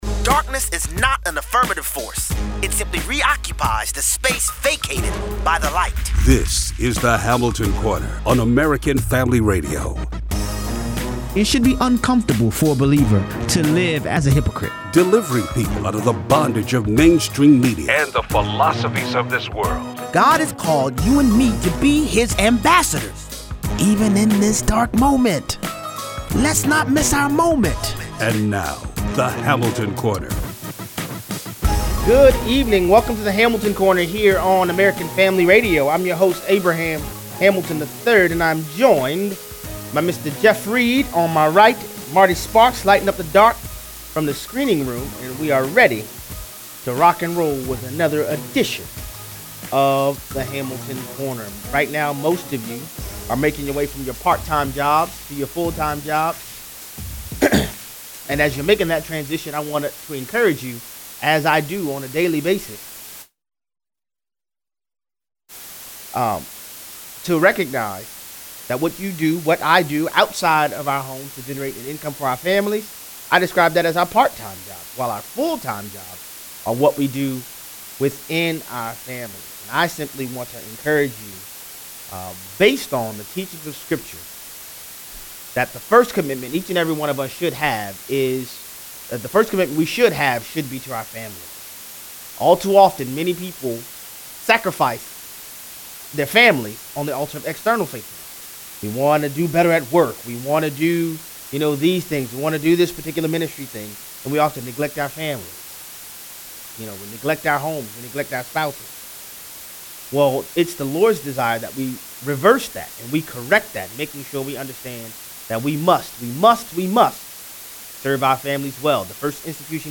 Chuck Todd signals the talking snake media’s abject failure propagating House Democrats’ impeachment efforts. 38:00 - 54:30. Maxine Waters doesn’t need facts. Callers weigh in.